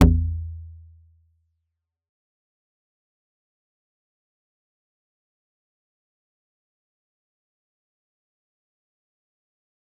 G_Kalimba-A1-f.wav